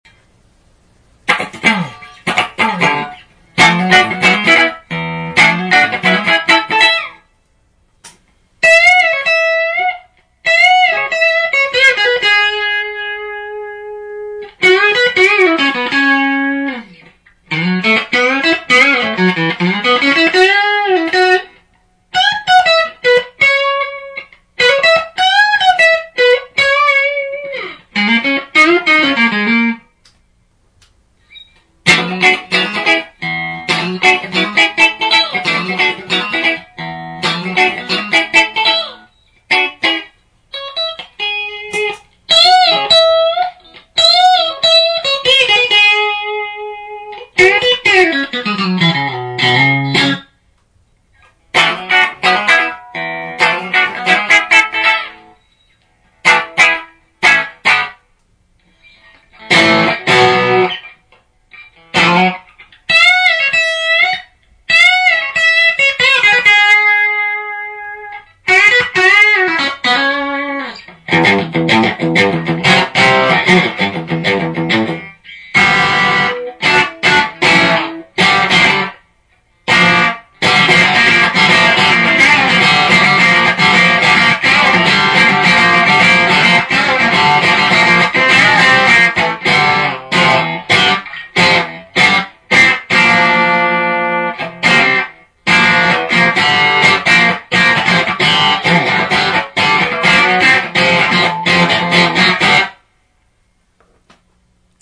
ギターからモガミのシールドケーブル(5m・スイッチプラグ付)→Carl MartinのHot Drive'n Boost→Whirlwindのシールドケーブル(1m)→Two-Rock Topazの順です。
お待たせ致しました、正真正銘LEAD2の音です。
大好きなアンプと素晴らしいキャビのおかげで補正されてはいますが、よく言えばキラキラした音、悪く言えば(以下略)。
実はこの音源のLEAD2、通常のSTなどのセッティングに倣い、フロント・リアとも1弦側を高めにして、その後音量バランスをとっているのです。